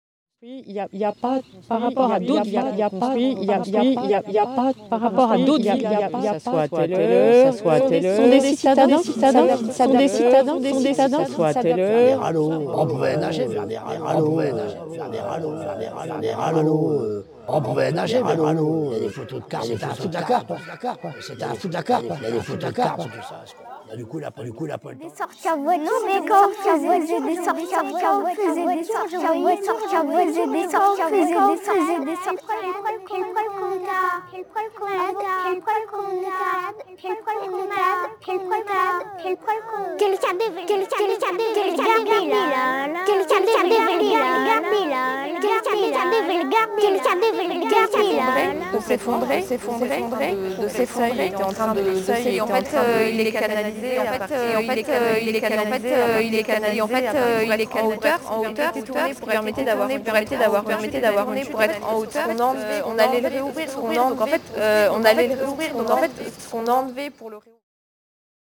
Avec les patches PureData ExMM93-PlayGrain2-3